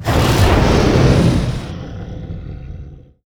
wav / general / combat / creatures / dragon / he / taunt1.wav
taunt1.wav